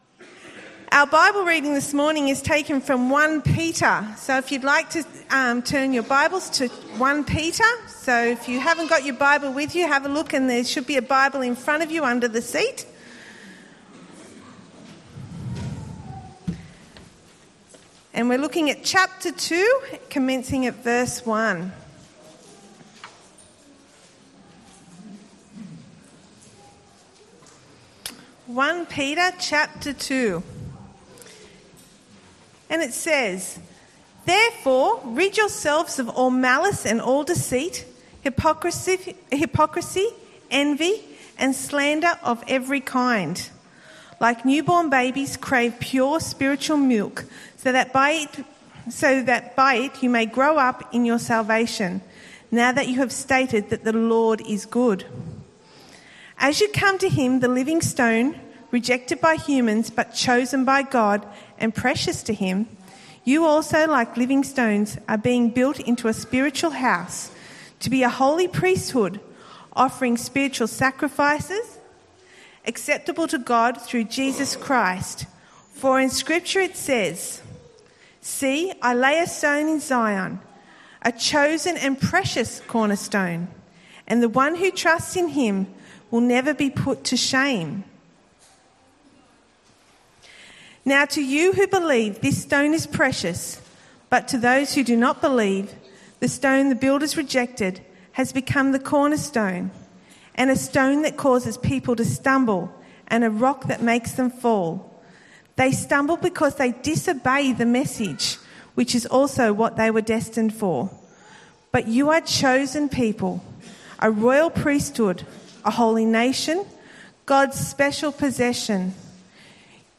Bible Messages